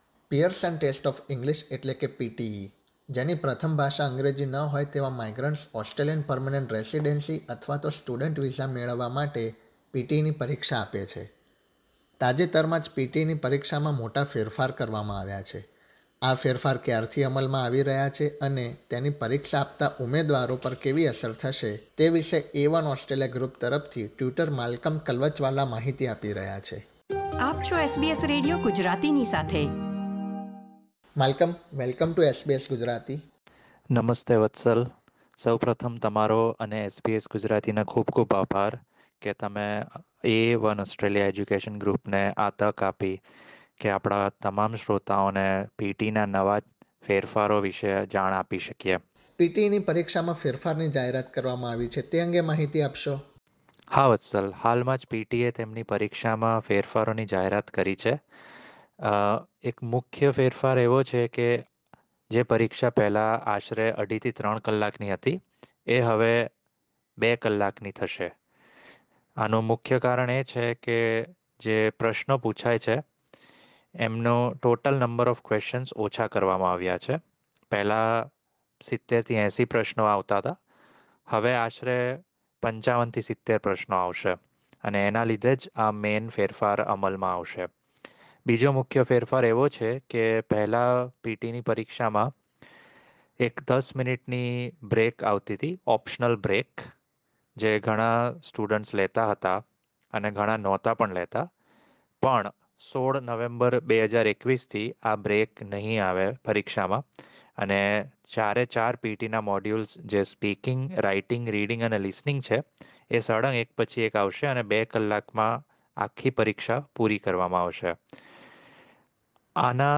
gujarati_1009_pteinterview.mp3